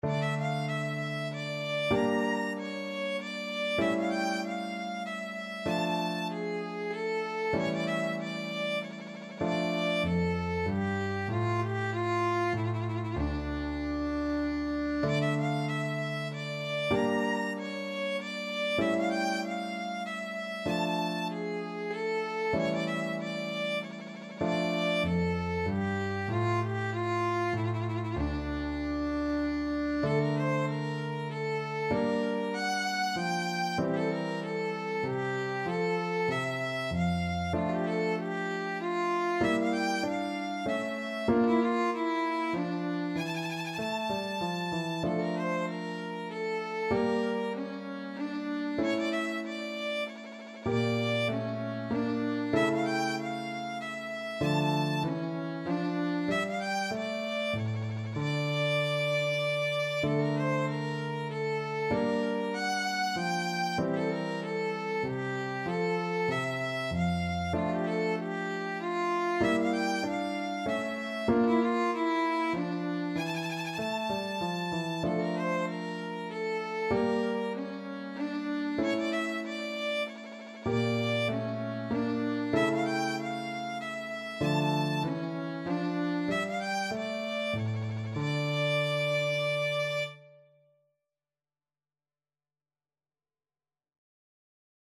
Violin
D minor (Sounding Pitch) (View more D minor Music for Violin )
3/8 (View more 3/8 Music)
Db5-Bb6
Classical (View more Classical Violin Music)
scarlatti_k23_sonata_VLN.mp3